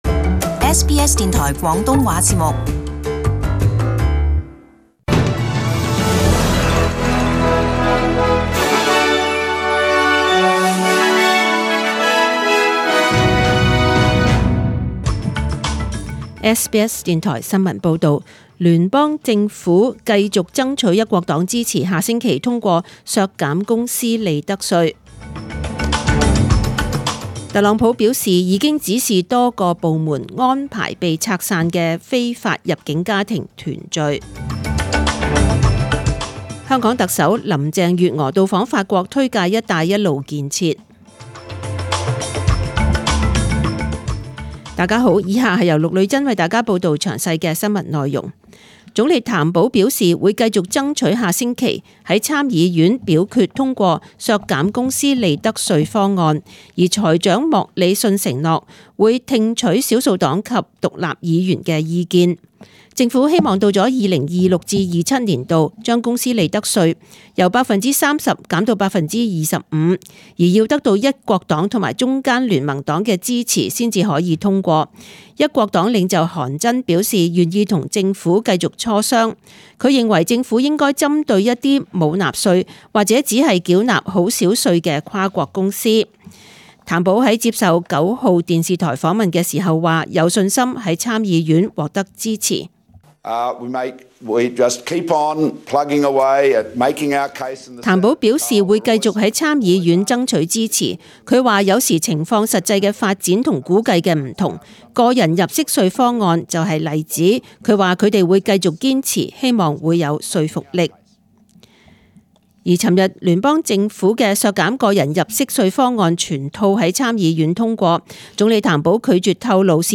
SBS中文新闻 （六月二十二日）
请收听本台为大家准备的详尽早晨新闻。